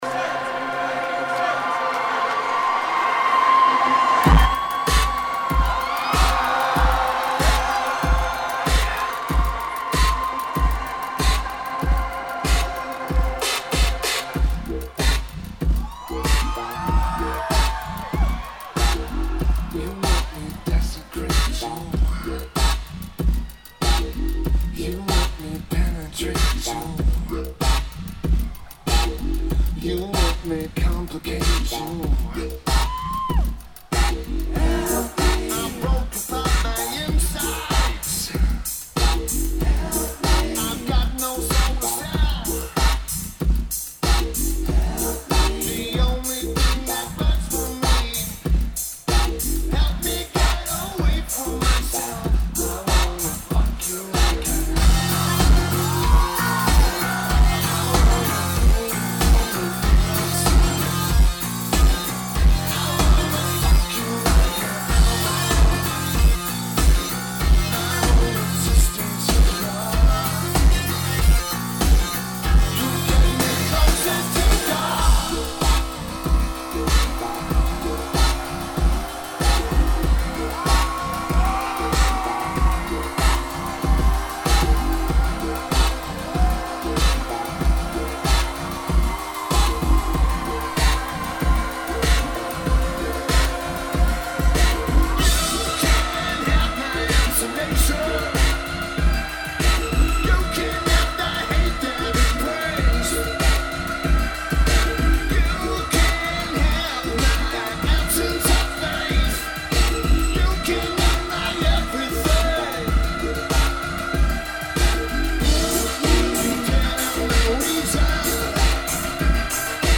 Lineage: Audio - AUD (SP-CMC-8 + Edirol R09HR) + (Zoom Q3HD)